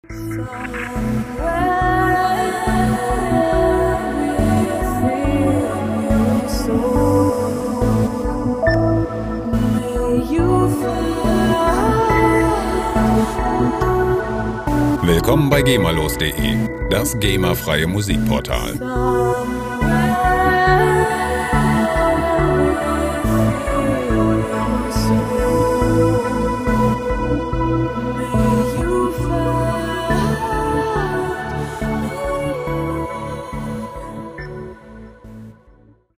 Ambient Loops gemafrei
Musikstil: Vocal Ambient
Tempo: 70 bpm